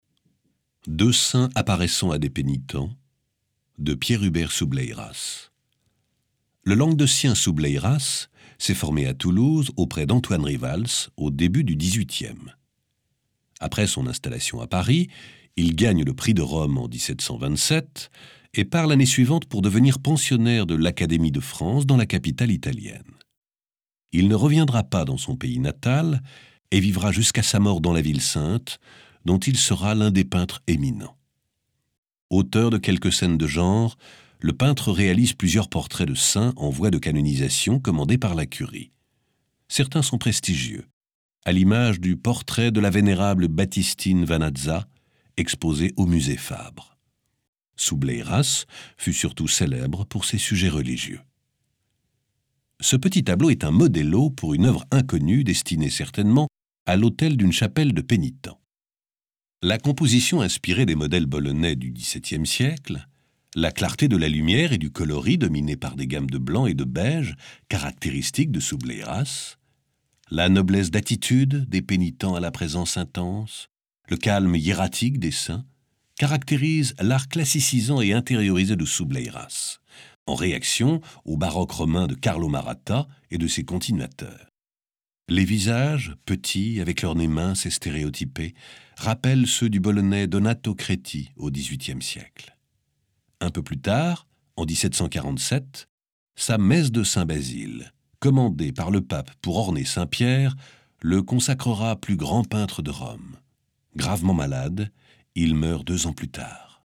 L'Idéal classique Musée Fabre, Montpellier, 5 novembre 2005 - 7 mai 2006 Audioguide FR Audioguide EN